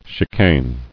[chi·cane]